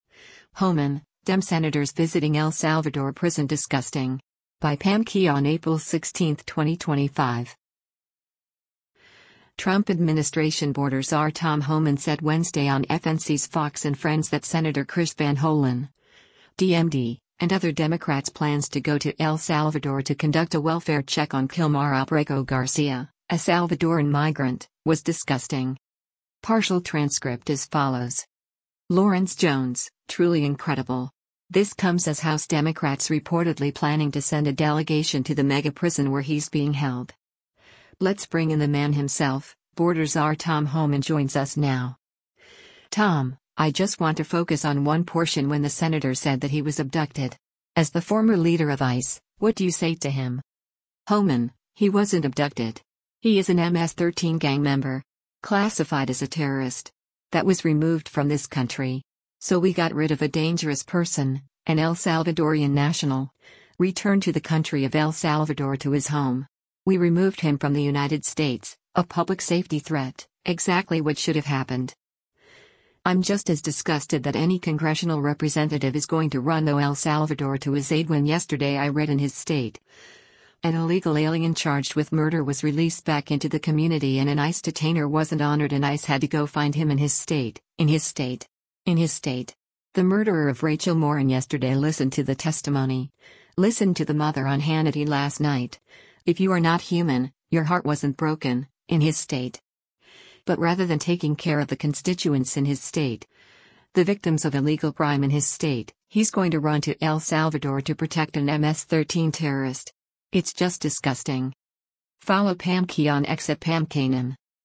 Trump administration border czar Tom Homan said Wednesday on FNC’s “Fox & Friends” that Sen. Chris Van Hollen (D-MD) and other Democrats’ plans to go to El Salvador to conduct a welfare check on Kilmar Abrego Garcia, a Salvadoran migrant, was “disgusting.”